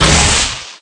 Thunder8.ogg